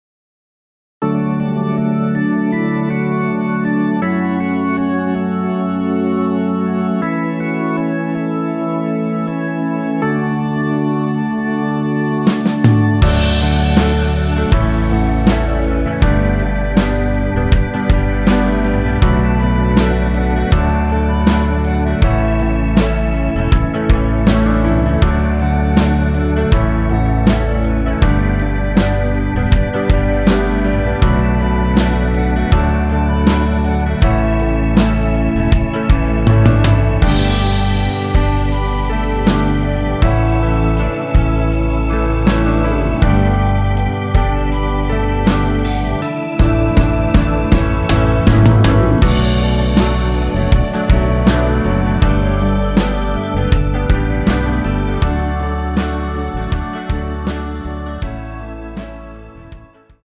원키에서(-1)내린 멜로디 포함된 1절후 바로 후렴으로 진행 됩니다.(아래의 가사를 참조하세요)
◈ 곡명 옆 (-1)은 반음 내림, (+1)은 반음 올림 입니다.
앞부분30초, 뒷부분30초씩 편집해서 올려 드리고 있습니다.